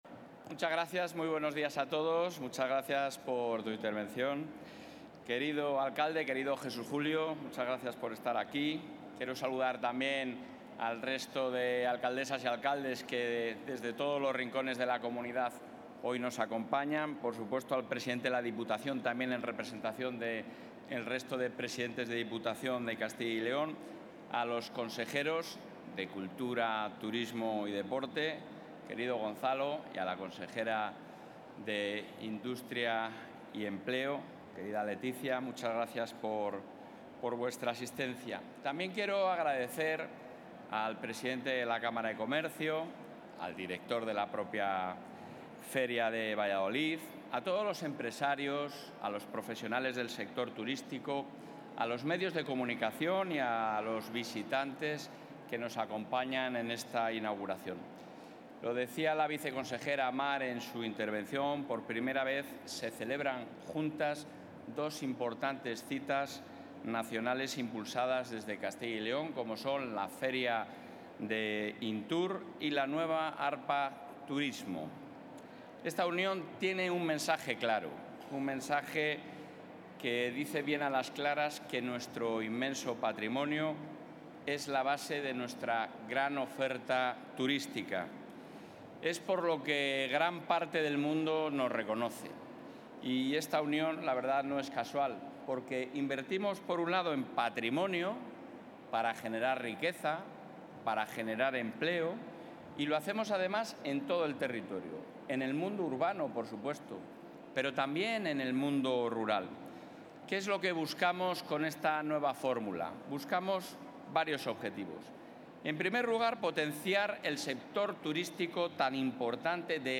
Intervención del presidente de la Junta.
El presidente de la Junta de Castilla y León, Alfonso Fernández Mañueco, ha inaugurado hoy AR-PA Turismo Cultural e INTUR, dos ferias que, en esta ocasión, se celebran conjuntamente en el recinto de la Feria de Valladolid, con el objetivo de ensalzar el valor de la cultura, el patrimonio y el turismo; proyectar la imagen de Castilla y León en el mundo; y dinamizar el medio rural.